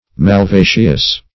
Malvaceous \Mal*va"ceous\, a. [L. malvaceus, from malva mallows.